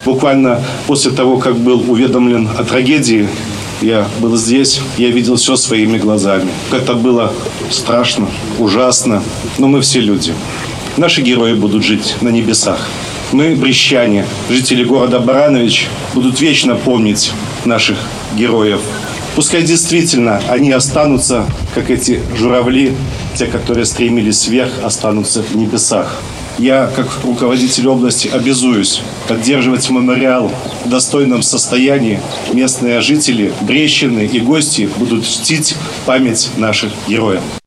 В годовщину со дня трагедии на месте, где завершился последний полет Героев, состоялась торжественная церемония с участием председателя Брестского облисполкома Юрия Шулейко, министра обороны Беларуси генерал-лейтенанта Виктора Хренина, помощника Президента – инспектора по Брестской области Валерия Вакульчика, мэра города Барановичи Юрия Громаковского.
Обращаясь к присутствующим, Юрий Шулейко отметил, что 26 лет назад на барановичской земле свой подвиг совершил первый Герой Беларуси Владимир Карват, показавший, что такое настоящий летчик и что значит для офицера долг и честь.